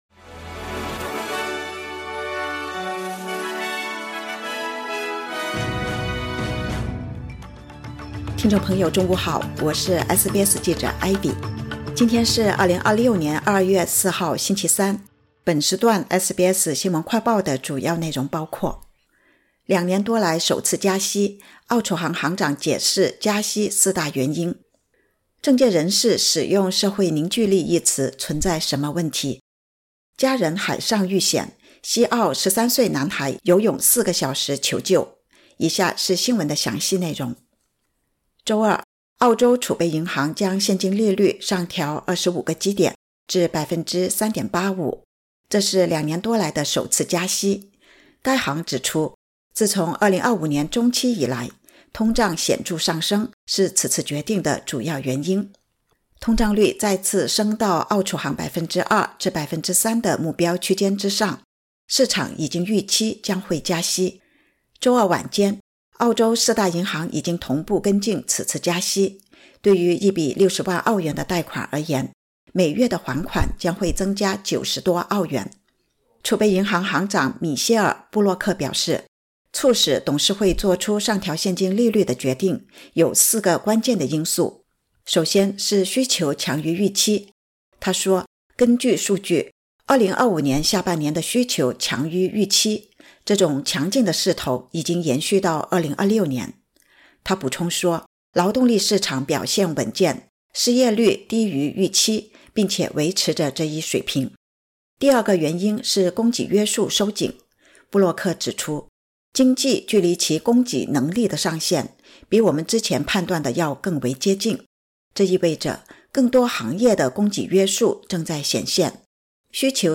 【SBS新闻快报】两年多来首度加息 澳储行行长剖析加息四大原因